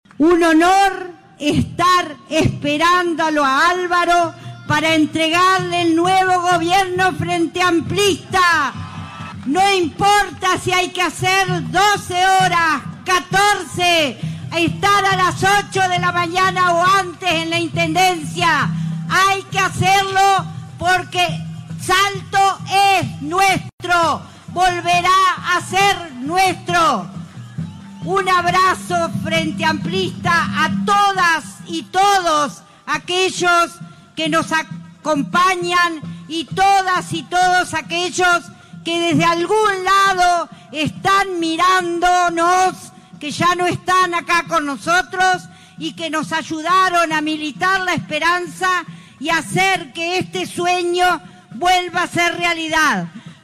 Multitudinario acto en la Plaza Estigarribia